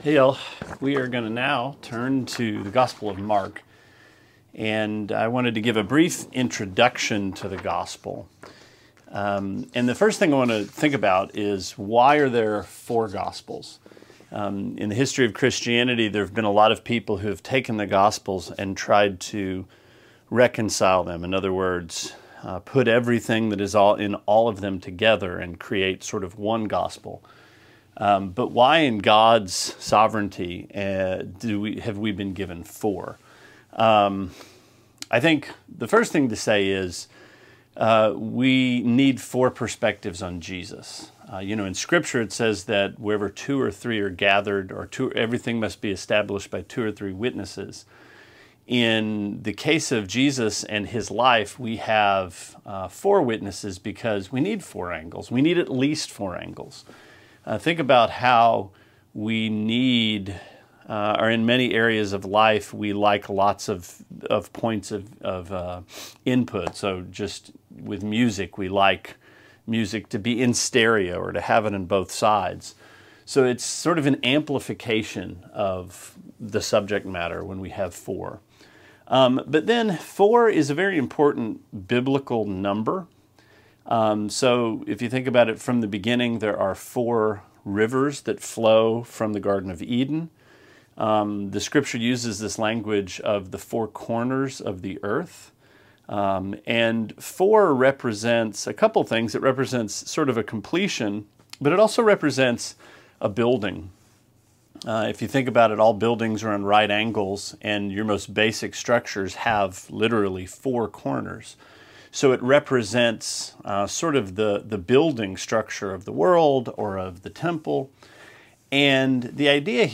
Sermonette A 6/11: Introduction to the Gospel of Mark